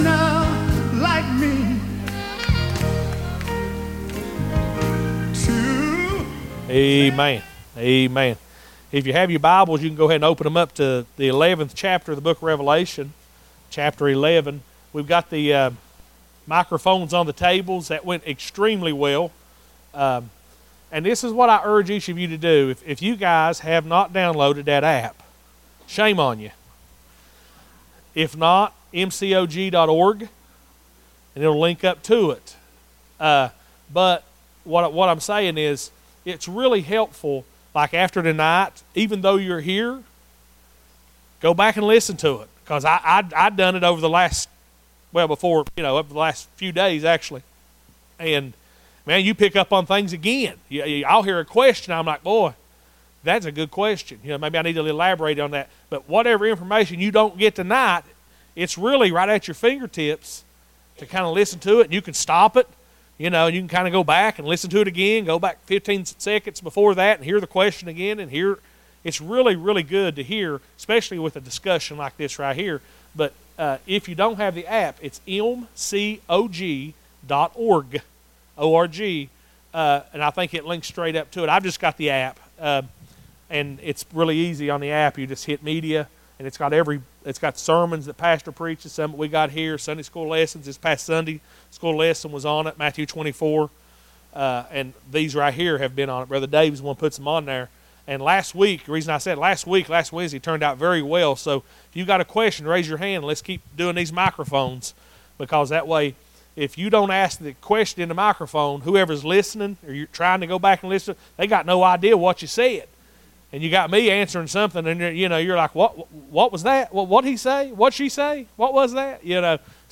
Summer Prophecy Series